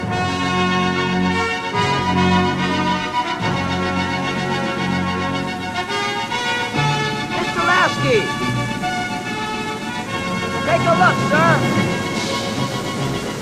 Here's a pitch shift example: a clip from The Final Countdown.
These are from the retail DVDs, both versions of which I own, not something I've manufactured.